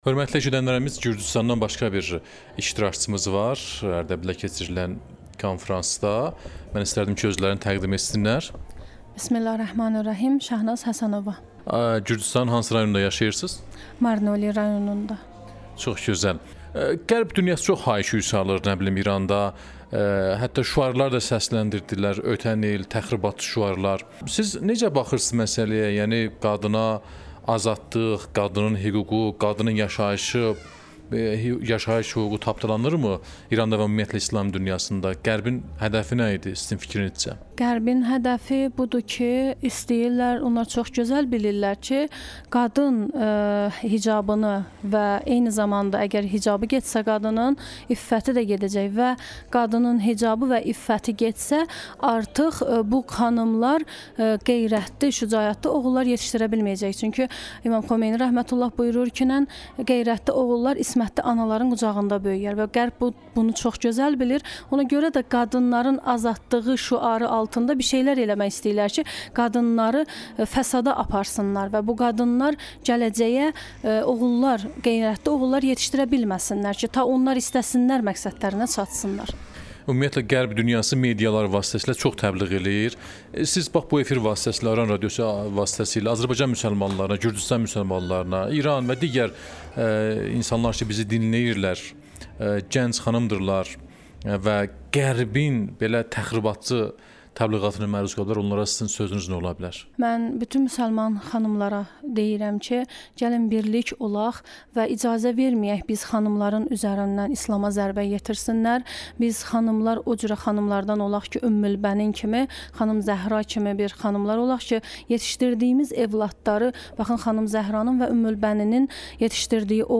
İranın Ərdəbil şəhərində qadınların hüquqları ilə bağlı keçirilən konfransın kənarında Aran Radiosuna müsahibə verib.